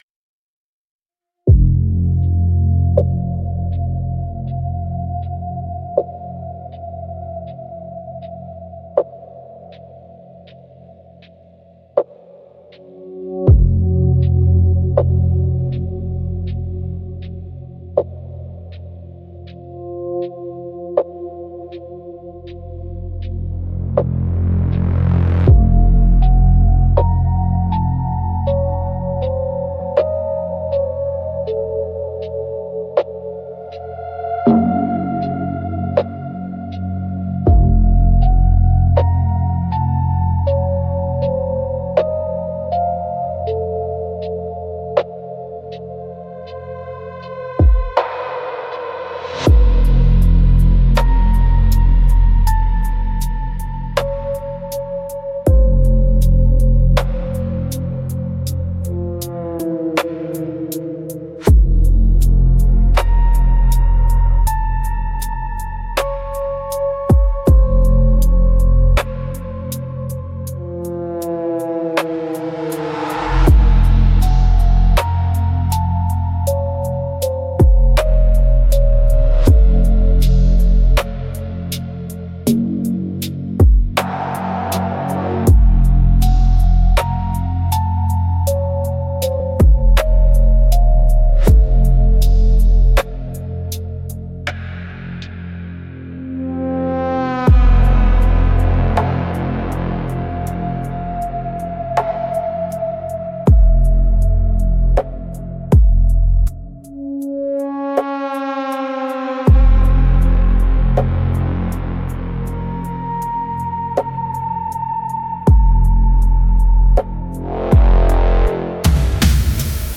> Click the music icon for dark music